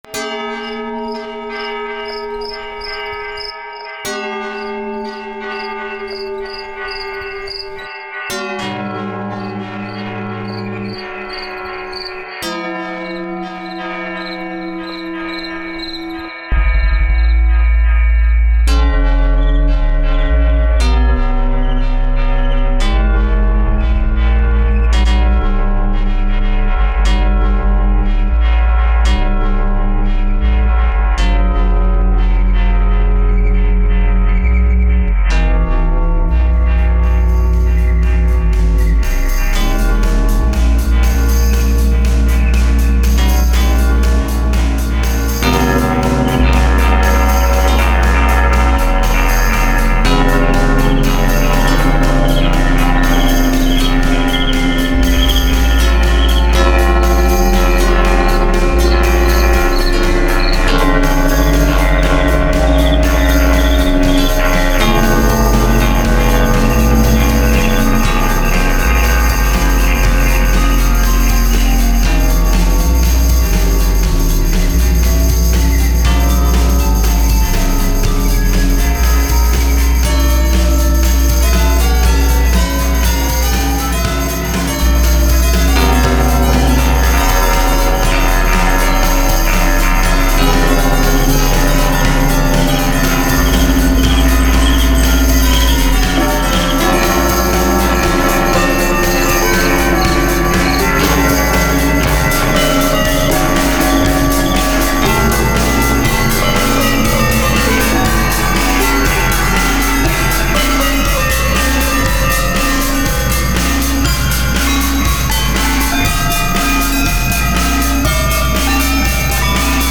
It’s noisy / clangy, but kind of musical.
Plus the song has some drums, synth bass
It’s kind of harsh, but I kind of love it.